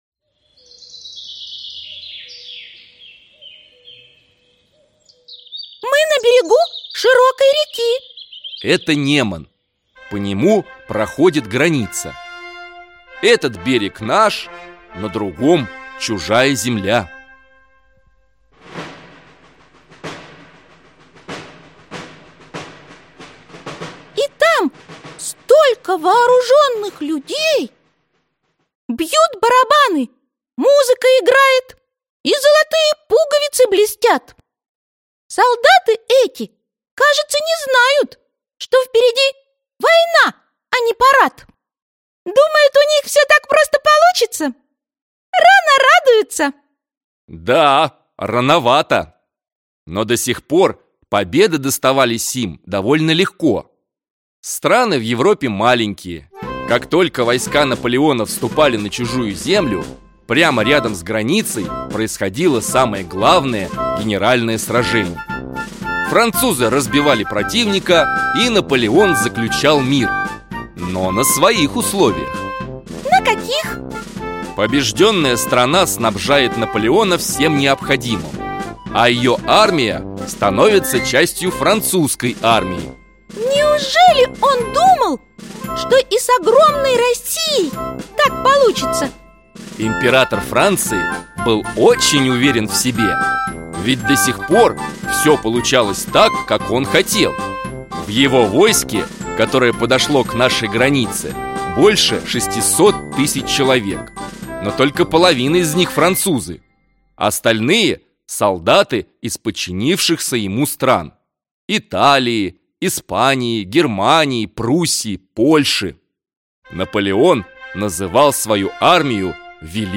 Аудиокнига Россия в 1812 году | Библиотека аудиокниг